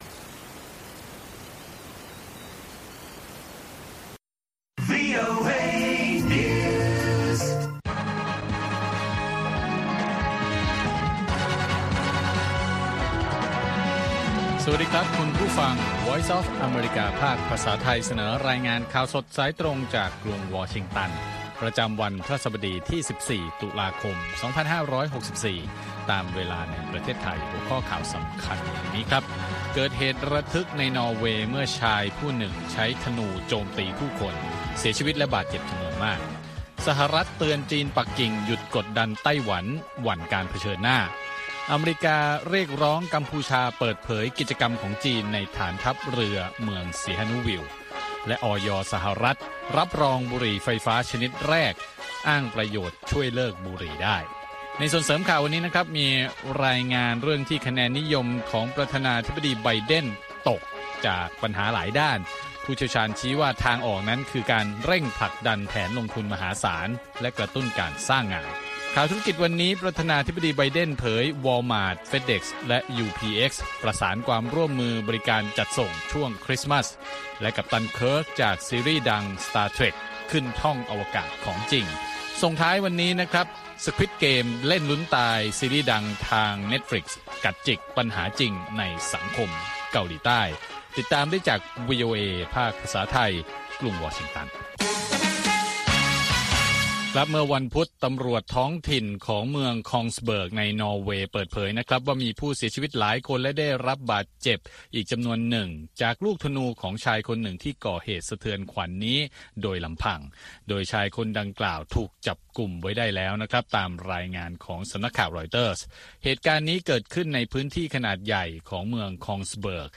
ข่าวสดสายตรงจากวีโอเอ ภาคภาษาไทย 8:30–9:00 น. ประจำวันพฤหัสบดีที่ 14 ตุลาคม 2564 ตามเวลาในประเทศไทย